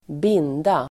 Uttal: [²b'in:da]